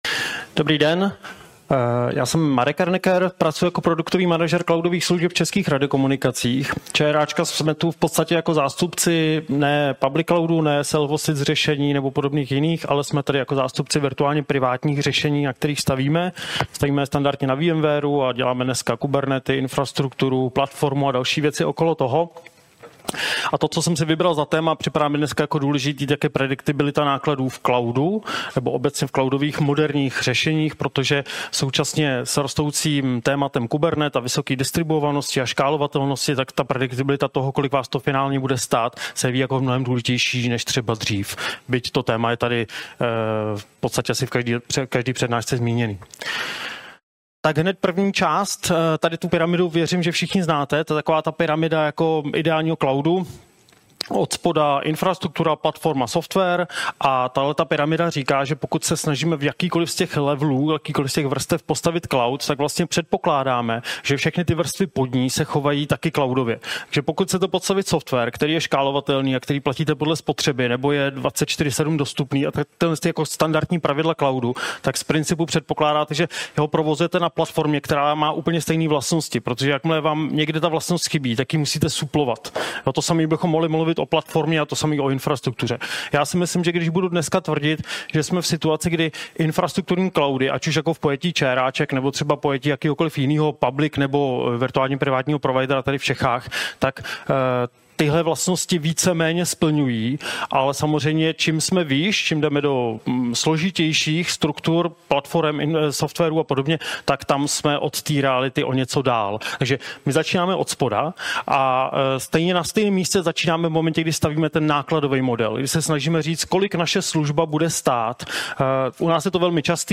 Webinář: Prediktibilita v cloudu - CRA Tech talks